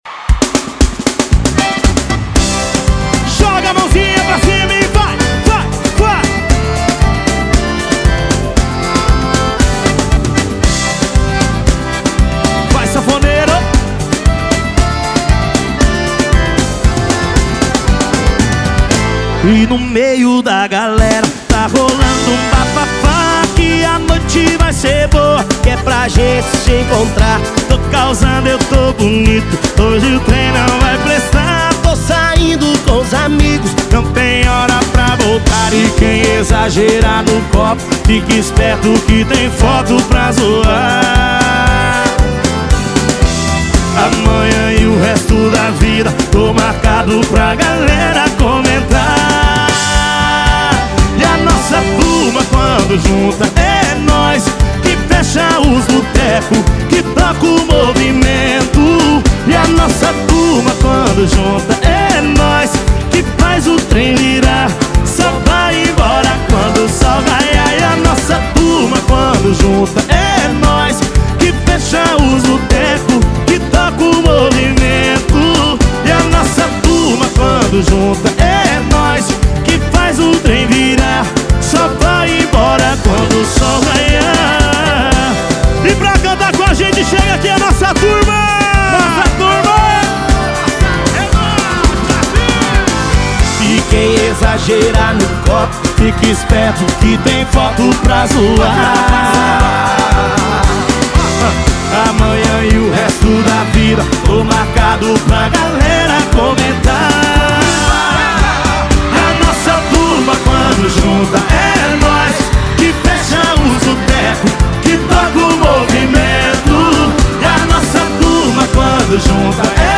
Top Sertanejo